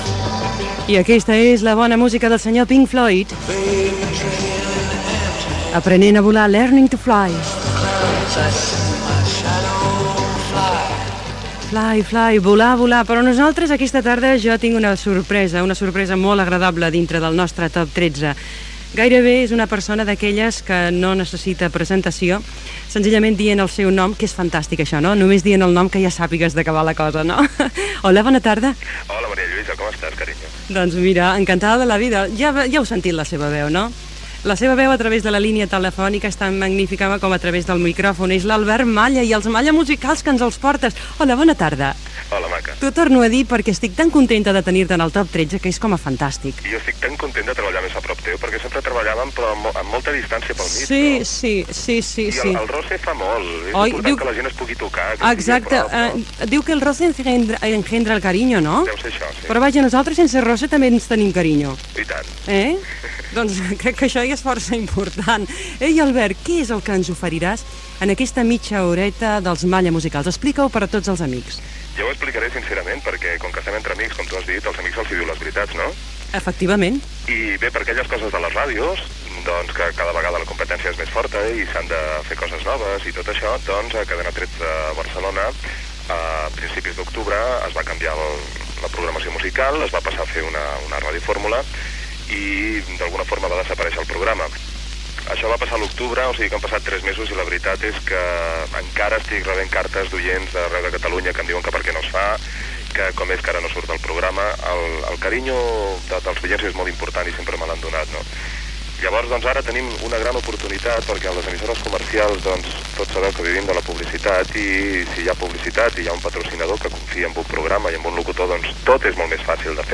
Tema musical, entrevista
Entreteniment
FM